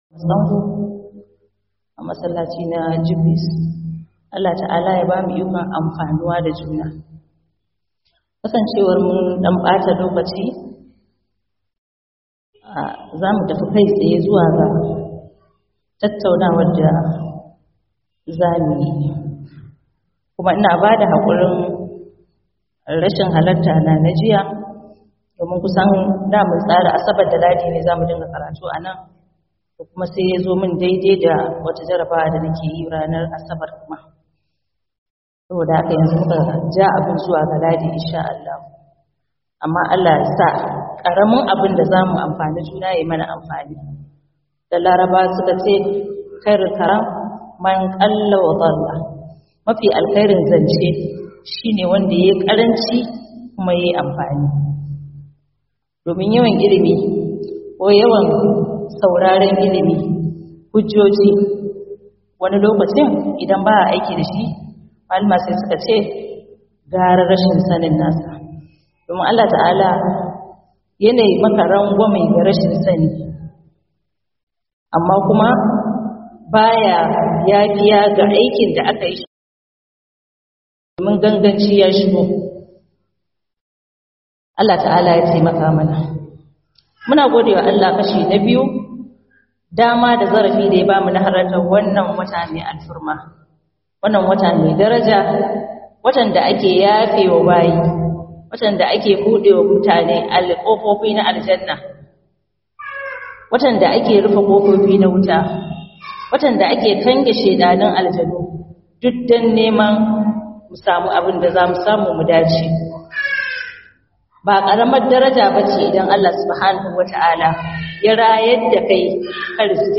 Ramadan Lecture